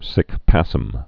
(sĭk păsĭm)